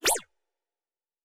Sci-Fi Sounds / Movement / Synth Whoosh 2_5.wav
Synth Whoosh 2_5.wav